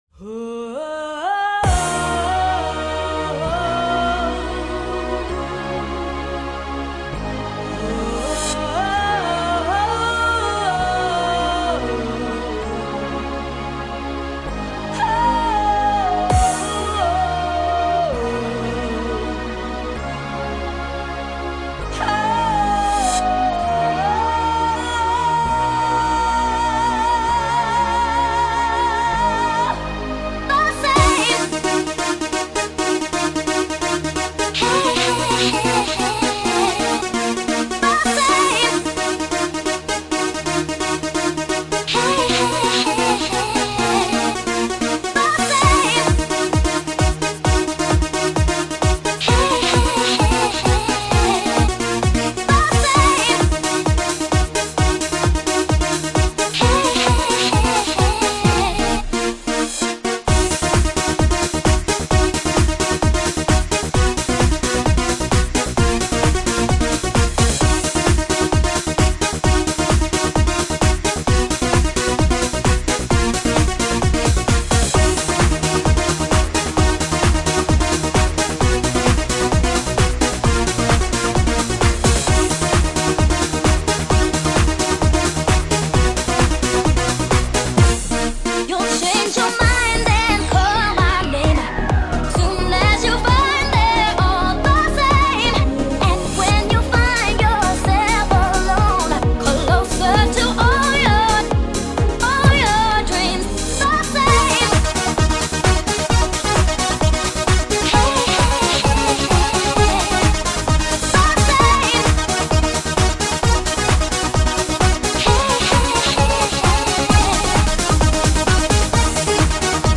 EuroDance 90-х